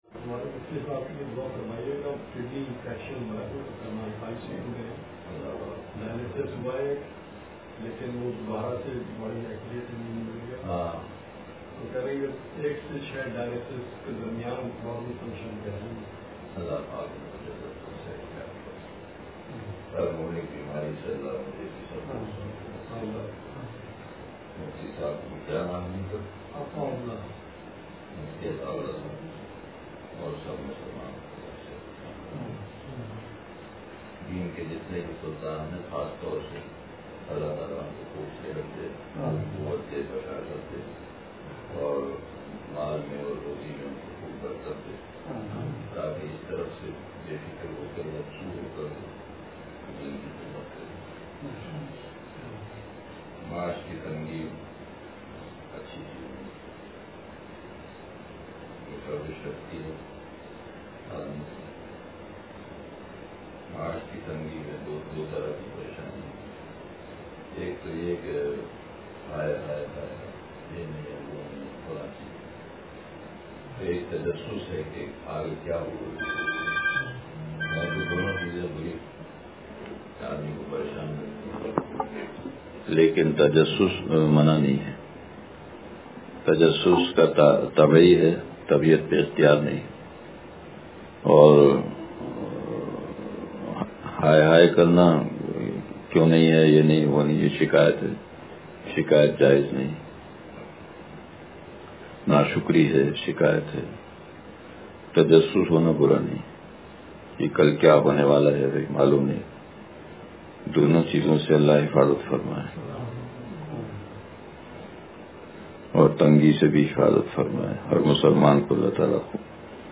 ذرہِ درد و غم ترا دونوں جہاں سے کم نہیں – مجلس بروز اتوار